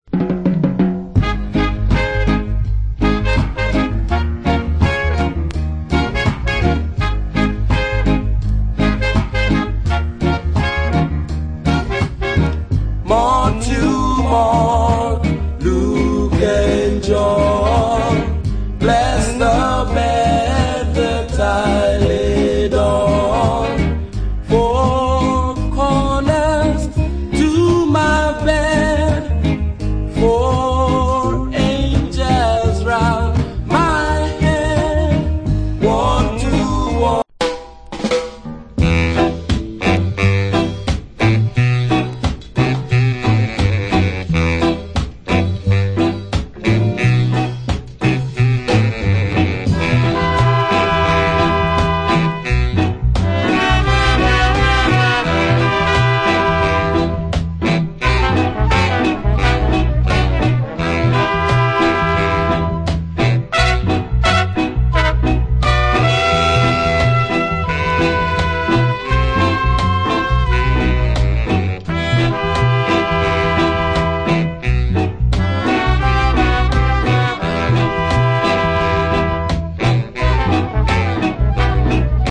1968 Rock Steady Vocal.